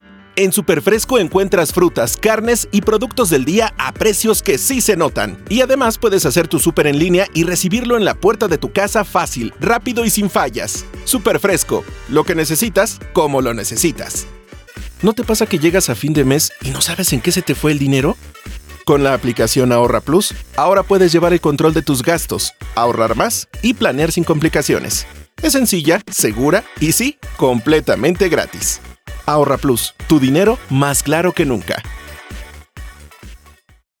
Demo comercial
- Micrófono de condensador Rode NT 1-A
- Estudio casero tratado para un audio limpio y con calidad de transmisión
Mediana edad
Adulto joven